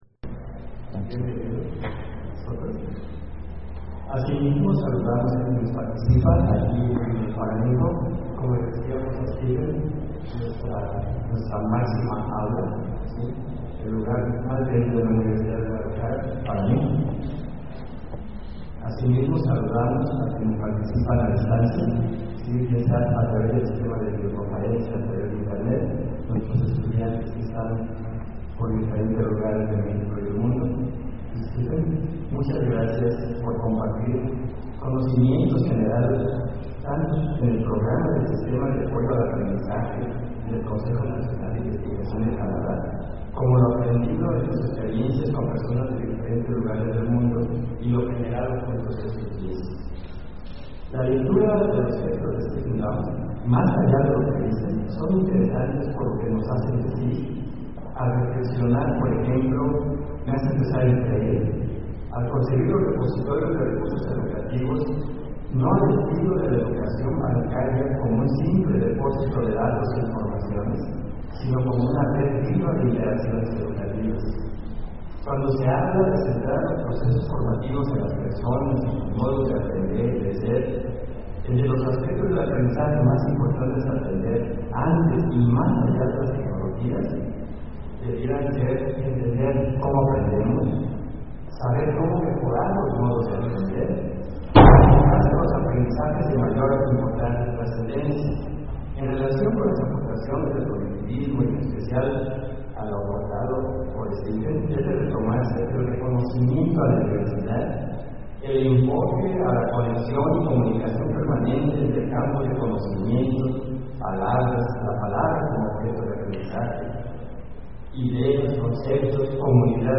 My audio is in English (my talk starts after the intros at around the 14 or 15 minute mark, 15:17 in the video).
Invited Talk, University of Guadalajara, Guadalajara, Mexico, Lecture, Aug 27, 2015.